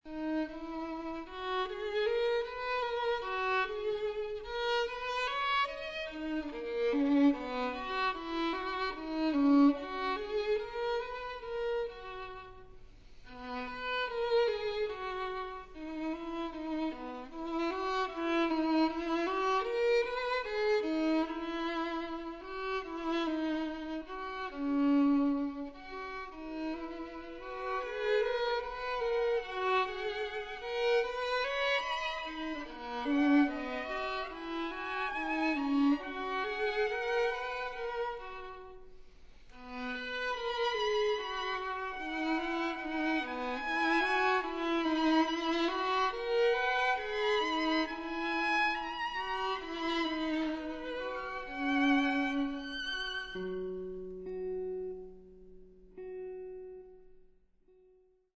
2 violins, electric guitar, cello